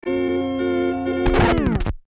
Converted sound effects
AA_throw_wedding_cake.ogg